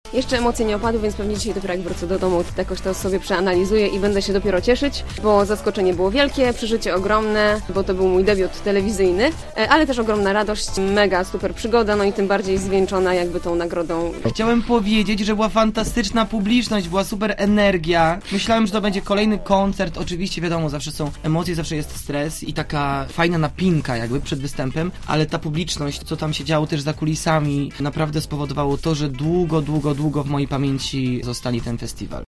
Oboje gościli dziś na antenie Radia Zielona Góra: